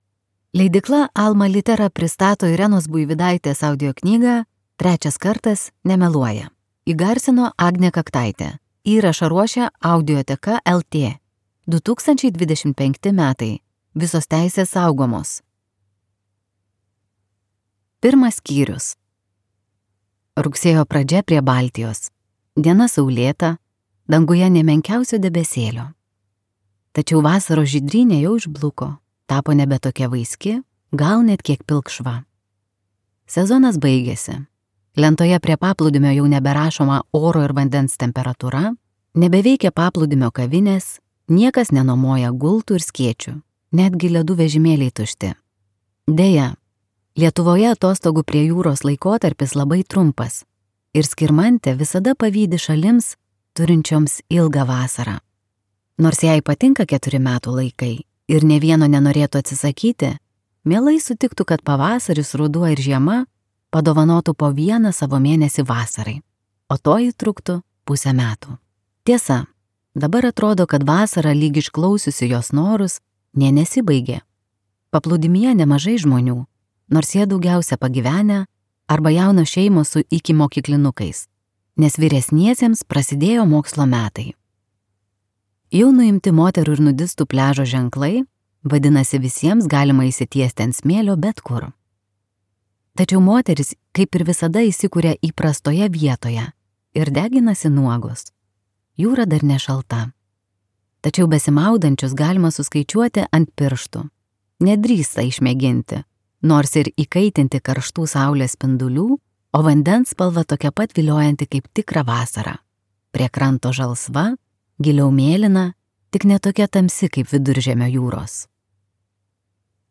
Trečias kartas nemeluoja | Audioknygos | baltos lankos